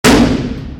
brick.ogg